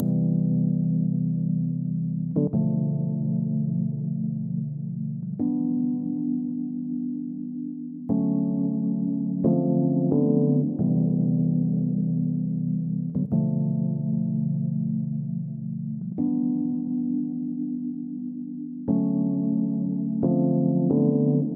只是一个罗德斯循环
描述：一些爵士乐的罗德斯和弦。